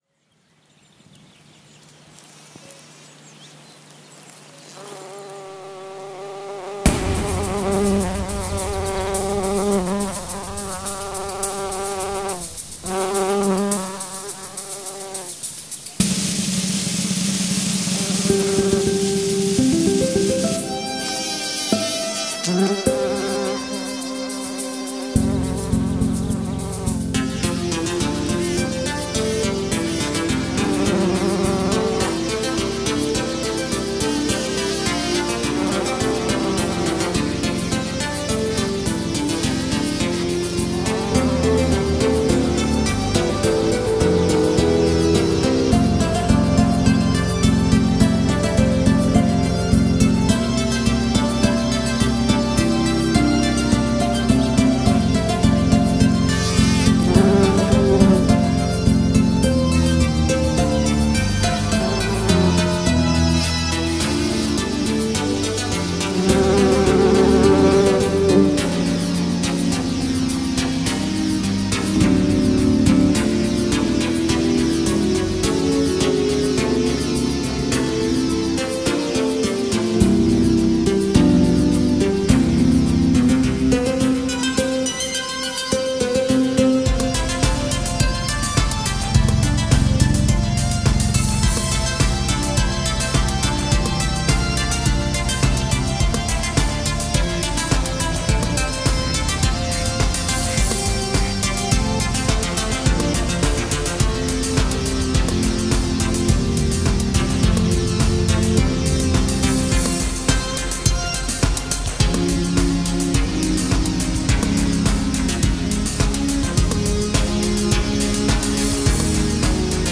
Nu med humlor, trädgårdssångare och bofink.